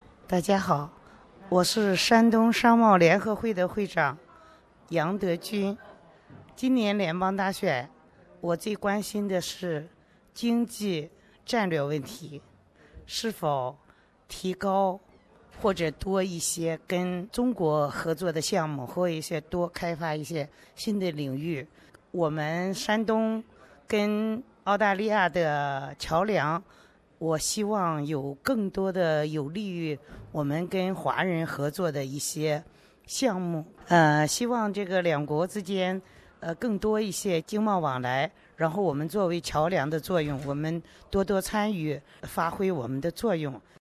- 录音音频：可用手机录音，也可在微信上分录两段话，总时间两分钟以内。